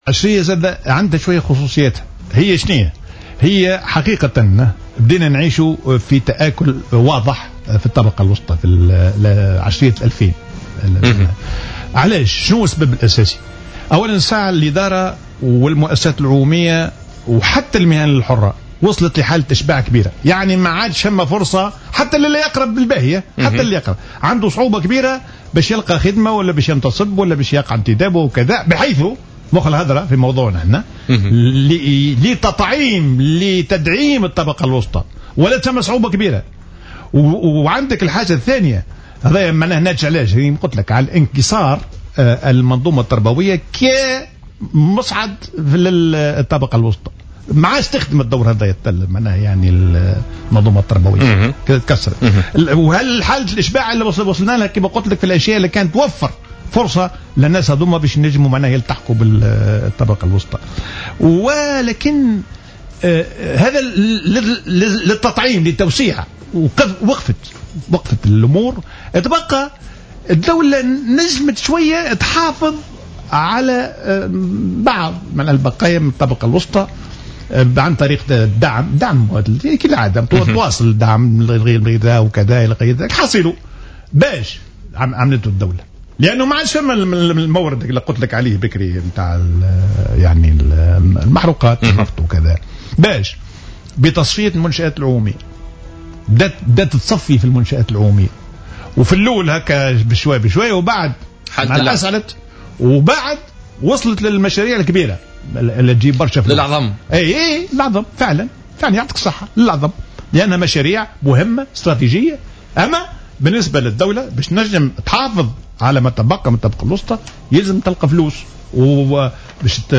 وأوضح الديماسي ضيف برنامج "بوليتيكا" اليوم الجمعة، أن الإدارات والمؤسسات العمومية وحتى المهن الحرّة وصلت إلى مرحلة "الإشباع" ولم يعد هناك فرص للعمل ، مشيرا إلى أن الانتدابات العشوائية والغير منطقية الحاصلة بعد الثورة بهدف "تطعيم" هذه الطبقة و انعدام ثقافة العمل وتعطيل دورة الإنتاج من خلال الاعتصامات والاحتجاجات قد زادت الأمر تعقيدا.